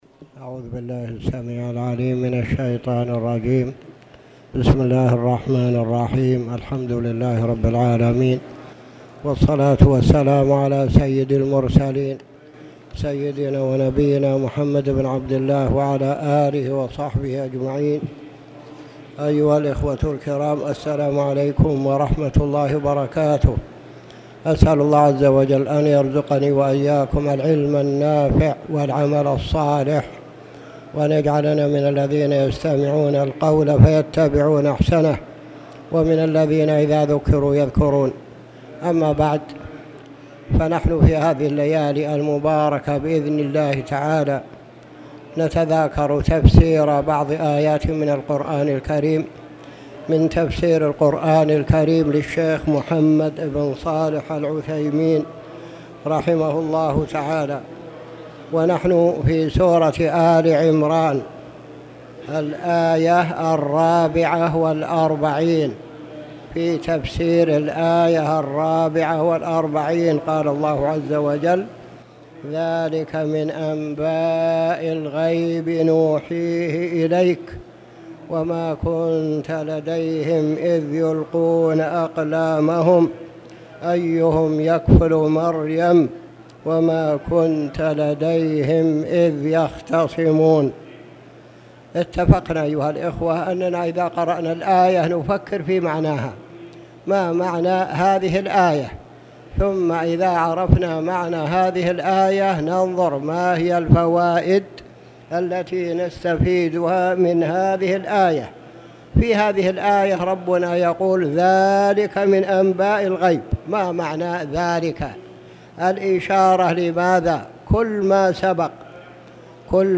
تاريخ النشر ٢١ جمادى الأولى ١٤٣٨ هـ المكان: المسجد الحرام الشيخ